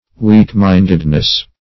-- Weak"-mind`ed*ness , n. [1913 Webster]